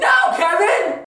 Voice clips
from a parody video